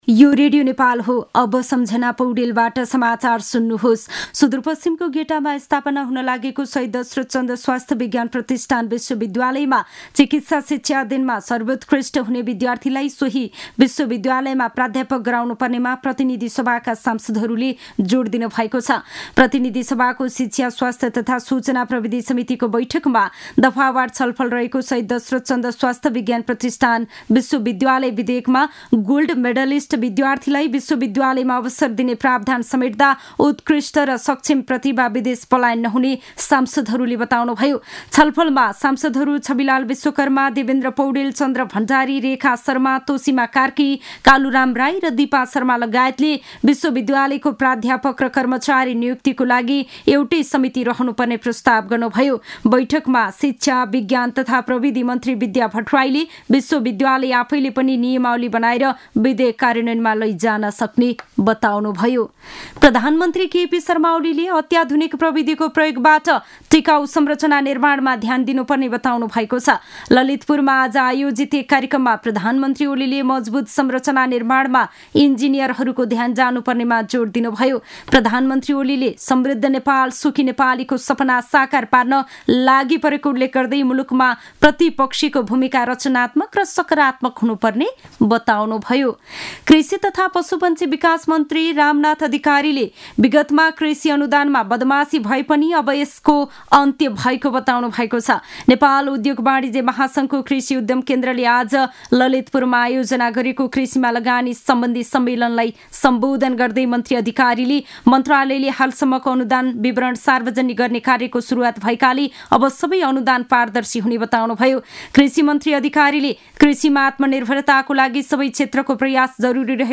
दिउँसो ४ बजेको नेपाली समाचार : १२ चैत , २०८१
4-pm-news-1-8.mp3